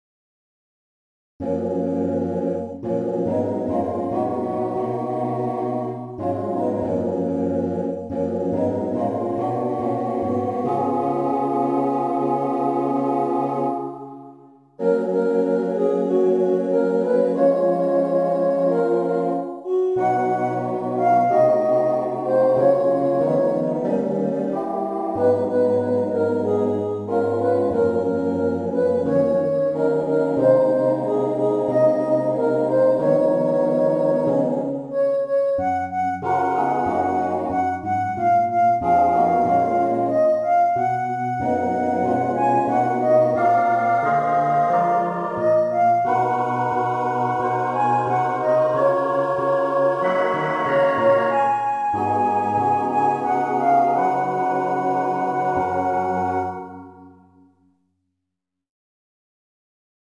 アカペラコーラス
とりあえず4枚＋ベースで簡潔に。
テンポと強弱、微妙な音の長さで調節するしかない。